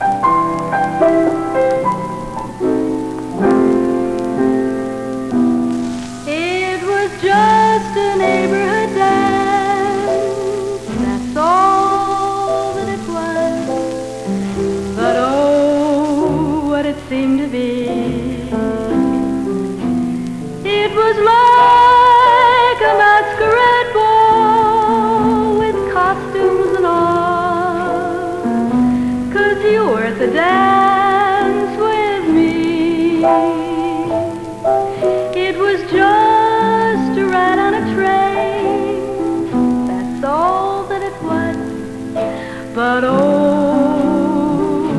※盤自体に起因するプチプチ音有り
Jazz, Pop, Easy Listening　USA　12inchレコード　33rpm　Mono